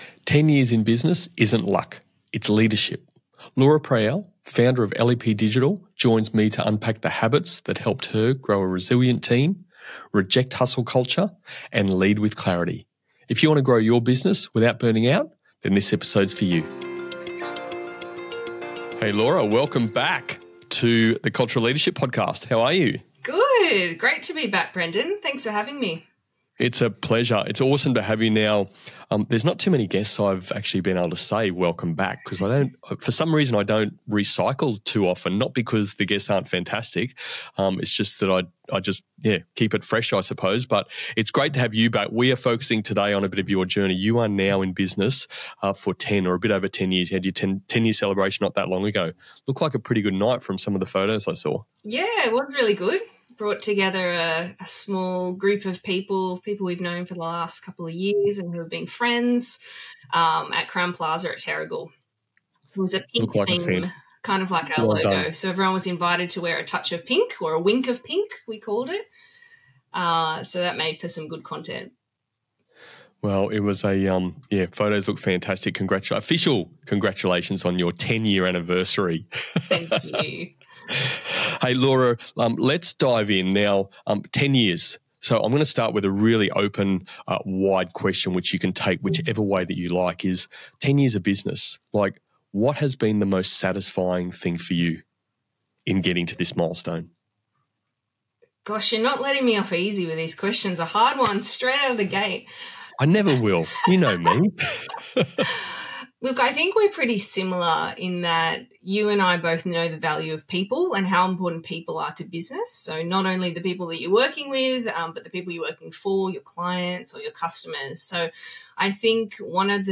This is a conversation packed with honesty, resilience, and practical insights that will inspire anyone building a business or leading a team.